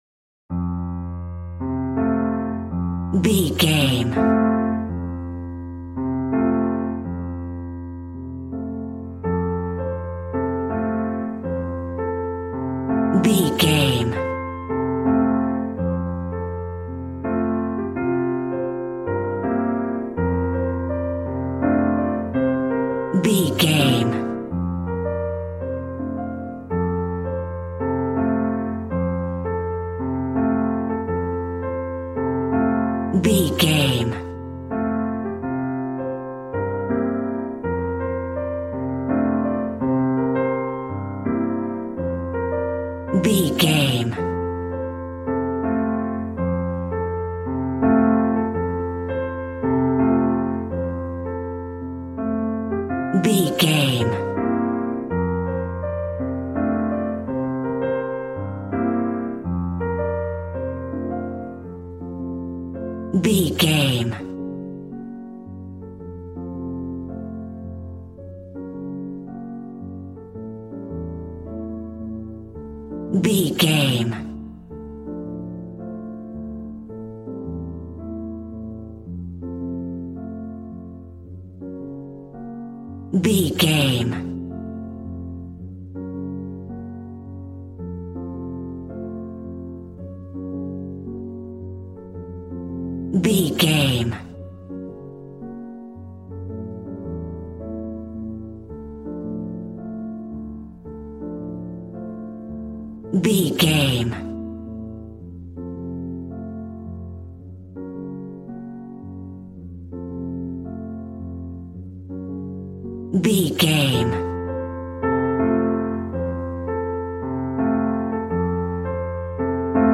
Smooth jazz piano mixed with jazz bass and cool jazz drums.,
Ionian/Major
cool
piano